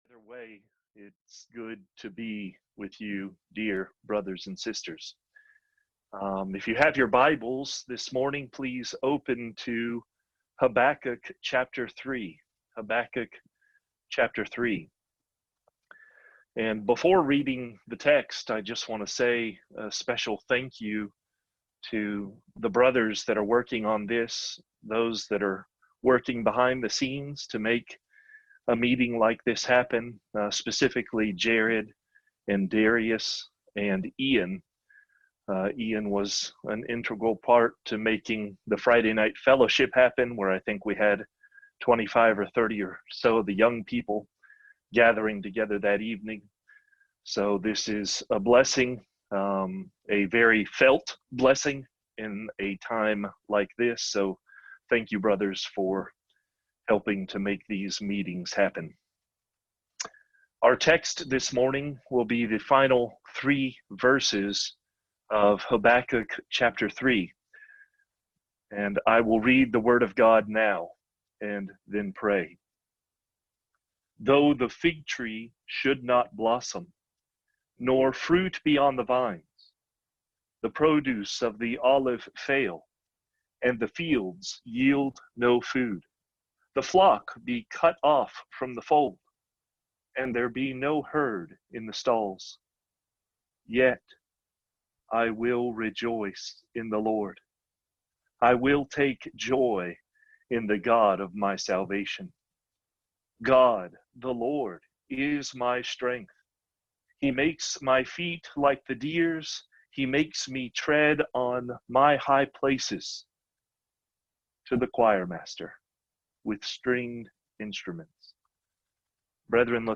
The Joyous Prophet | SermonAudio Broadcaster is Live View the Live Stream Share this sermon Disabled by adblocker Copy URL Copied!